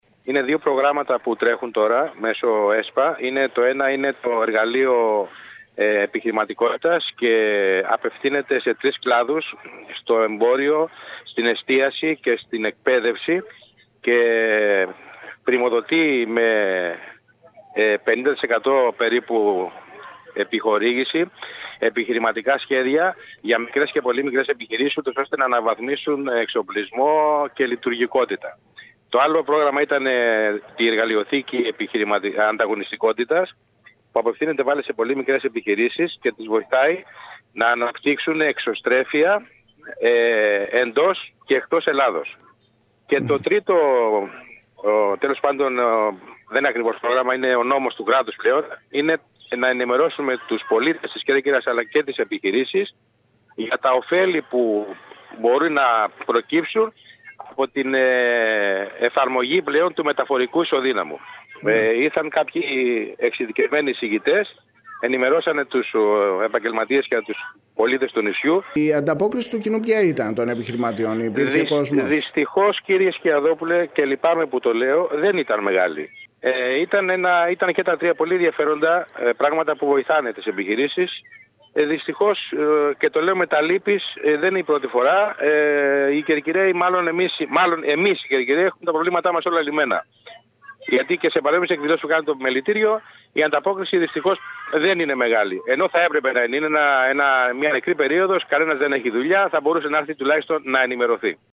Μιλώντας στο σταθμό μας